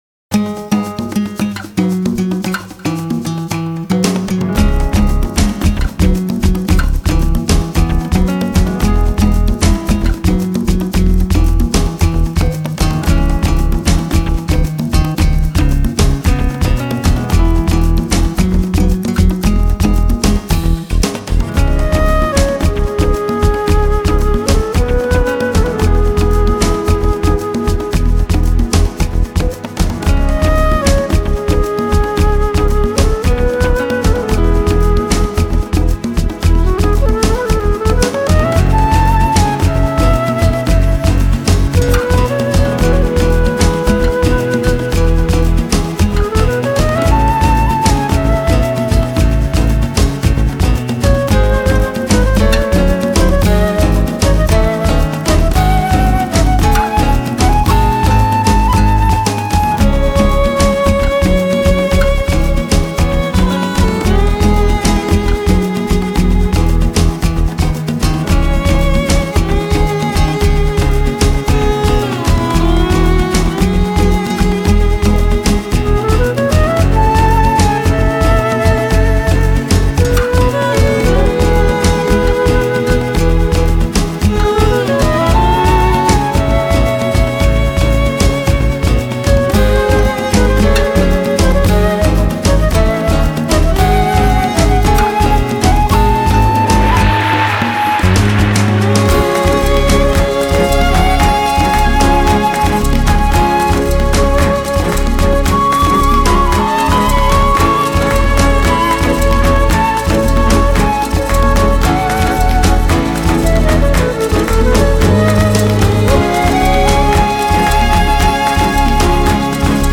新世紀長笛
神秘人声的萦绕忽隐忽现